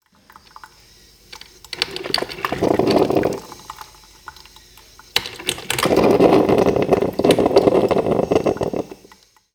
Kaffeemaschine
Das Wassertank der Kaffeemaschine (Filterkaffee) ist leer und damit der Kaffee in kürze fertig.